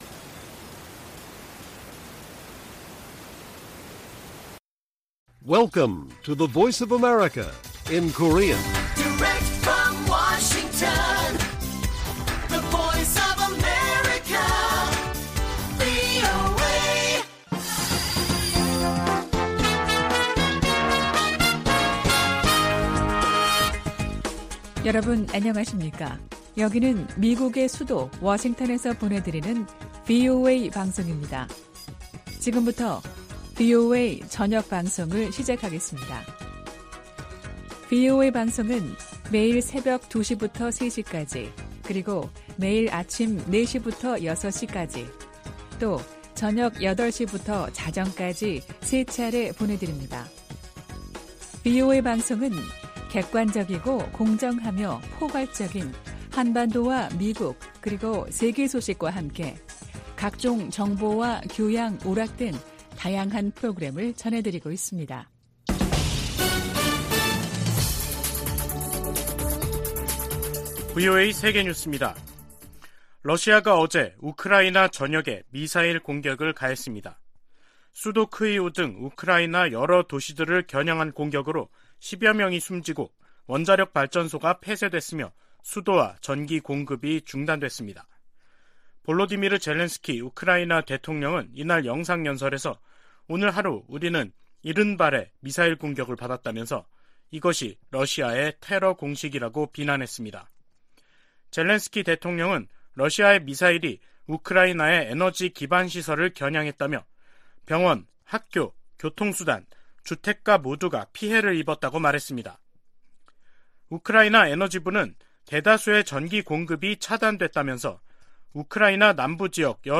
VOA 한국어 간판 뉴스 프로그램 '뉴스 투데이', 2022년 11월 24일 1부 방송입니다. 미 국무부는 미한 연합훈련에 대한 북한의 비난을 일축하며 북한이 방어적인 훈련을 구실로 불법 행동을 이어가고 있다고 지적했습니다. 한국 정부는 북한 김여정 노동당 부부장의 대남 비난담화에 도를 넘었다며 강한 유감을 표명했습니다.